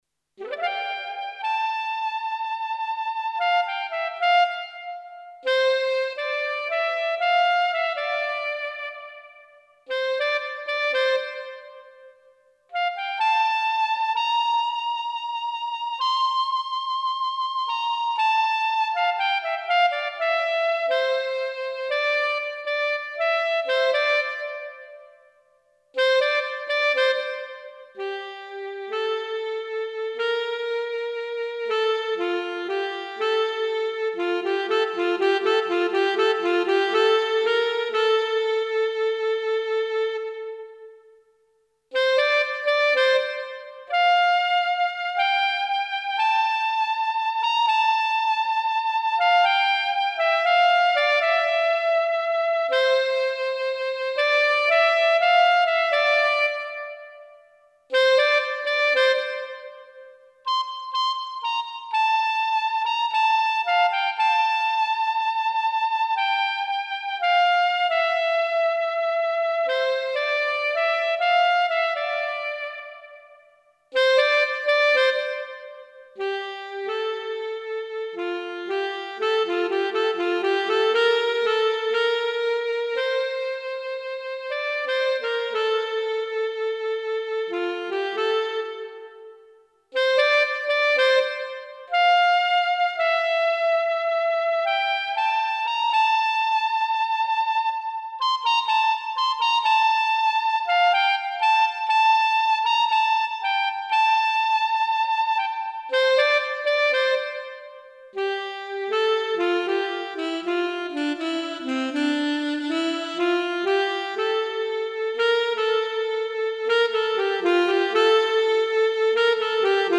Gechillte Saxophon Version.